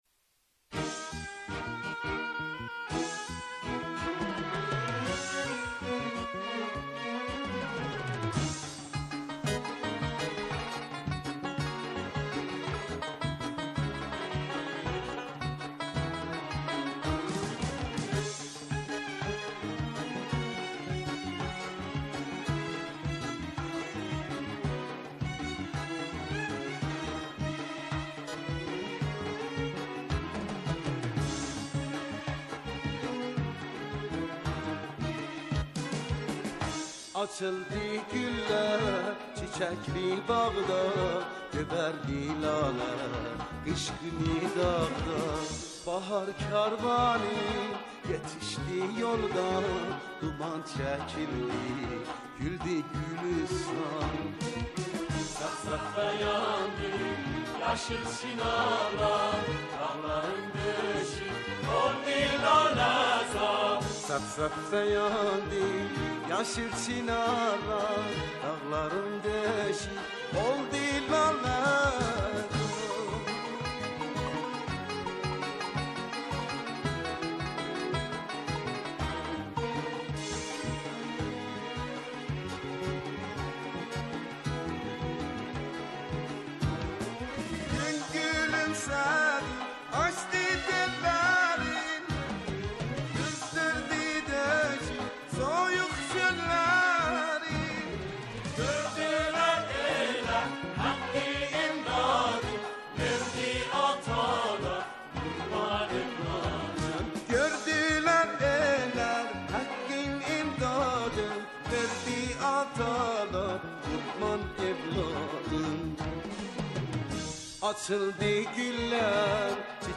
سرودهای دهه فجر